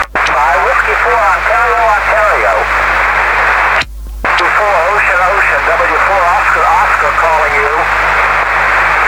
I have always had a tape recorder at hand in my shack.